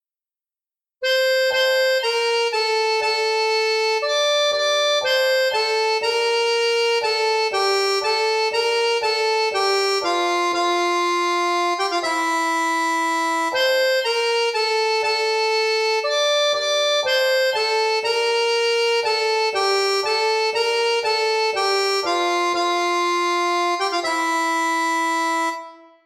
mellanspel
mellanspel follede hender.mp3